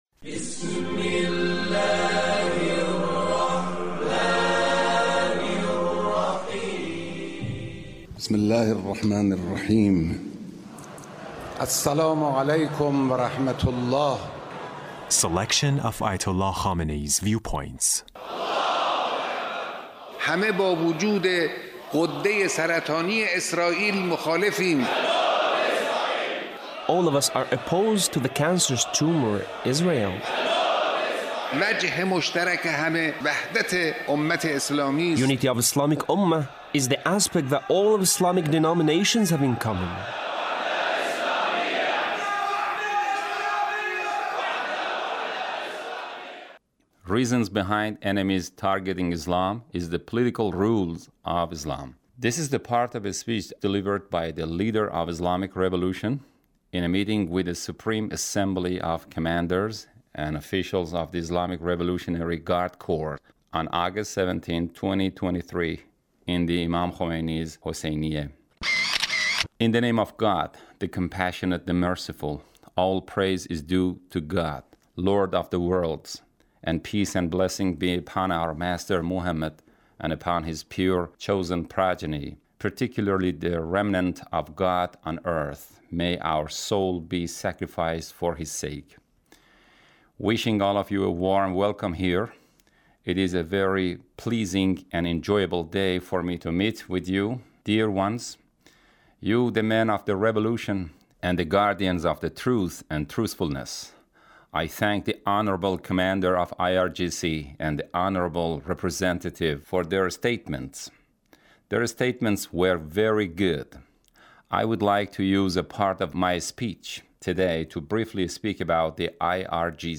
Leader's Speech (1805)